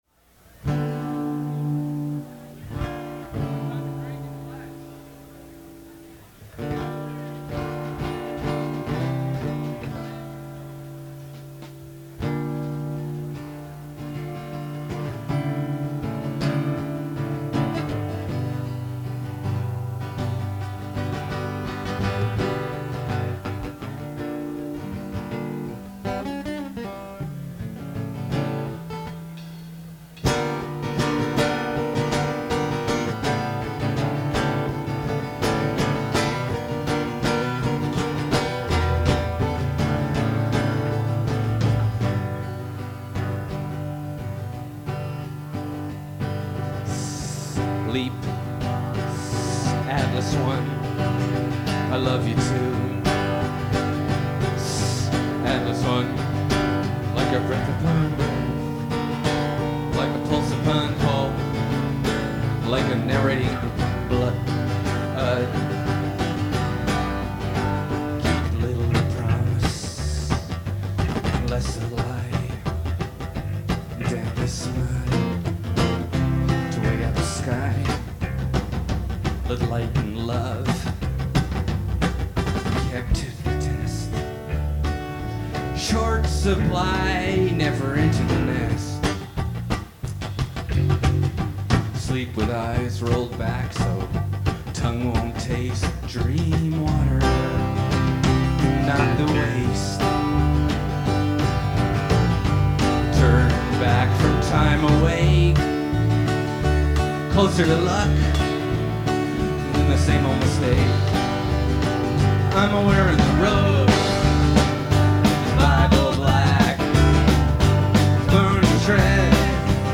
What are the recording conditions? live 1992-10-11, Vermotstress Festival, Burlington